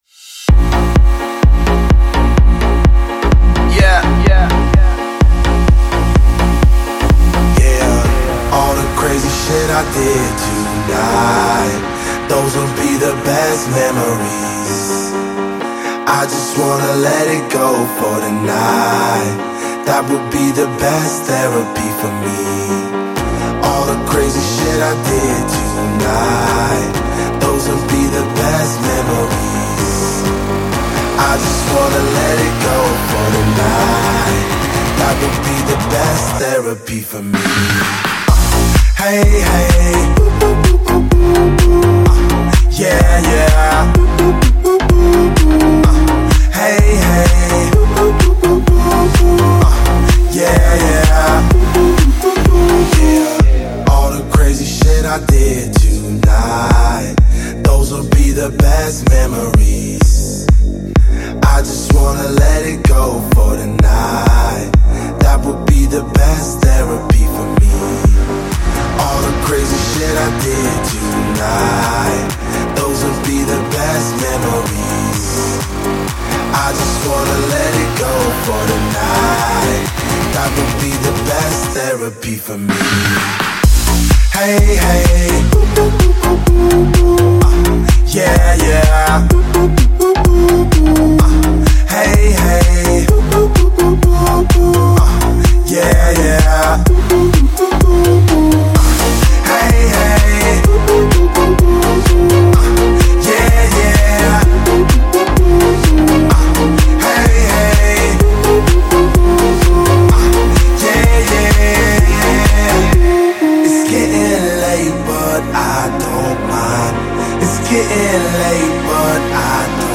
EDM